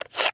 Звуки пульта от телевизора
Взяли в руку пульт от телевизора со стола